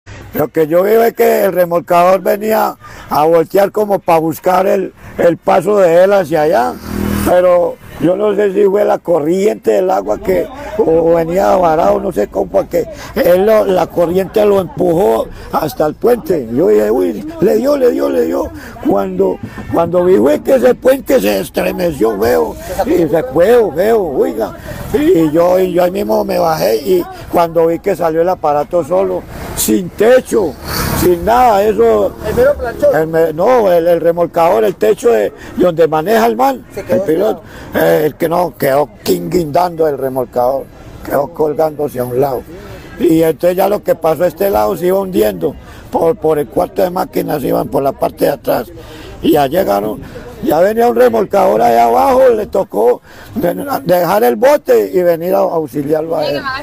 El impacto del remolcador no dejó víctimas, pero generó preocupación durante varias horas por posibles daños en la estructura del puente. Un testigo narró el momento de los hechos.